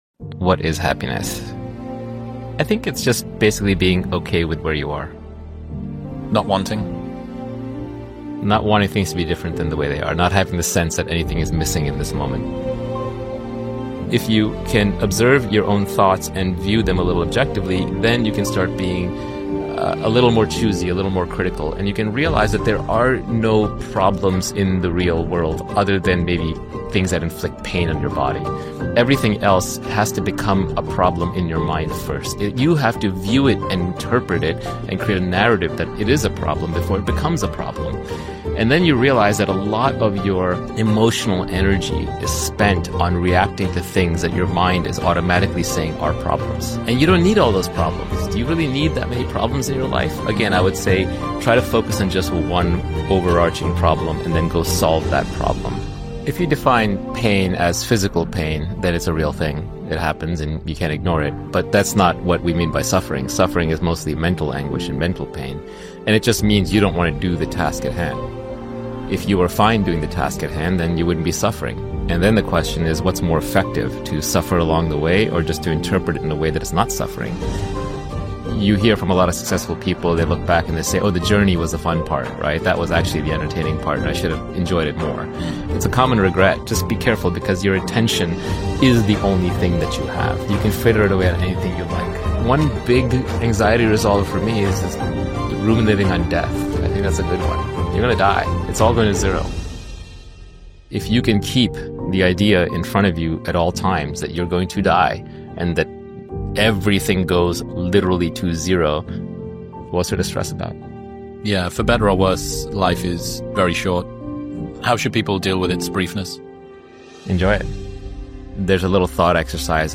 Naval Ravikant - What is happiness motivational speech